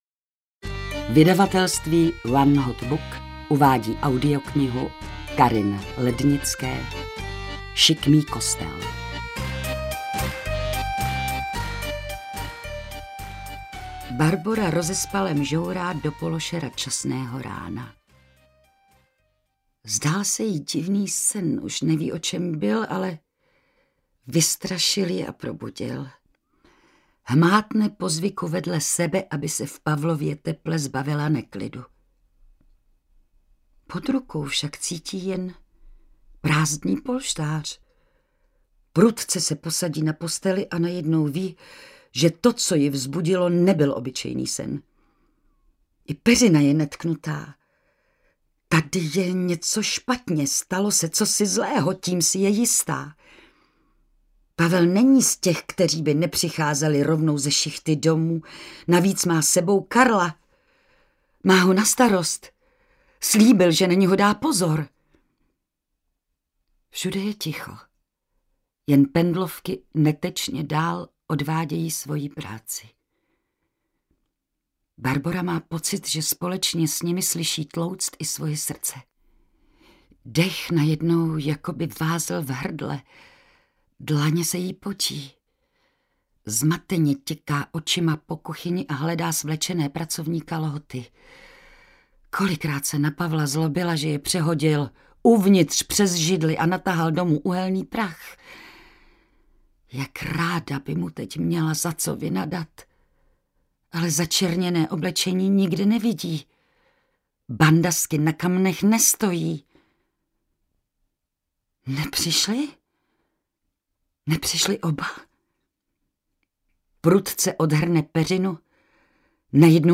Šikmý kostel audiokniha
Ukázka z knihy
sikmy-kostel-audiokniha